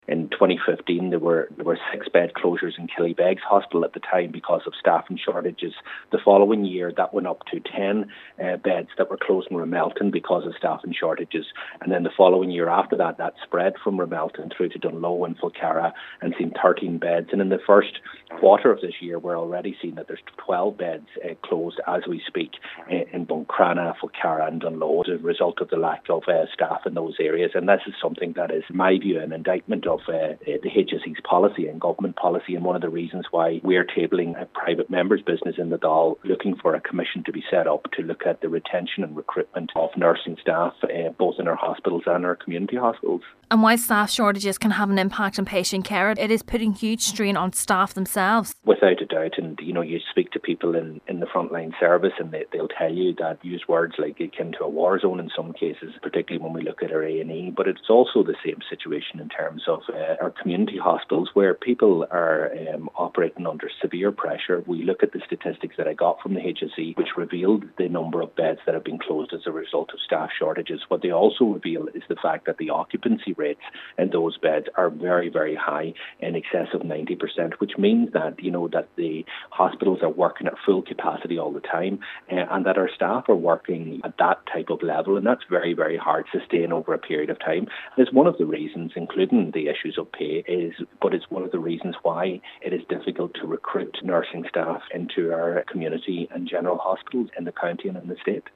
Donegal Deputy Pearse Doherty says the figures also reveal that the community hospitals are continually working at full capacity: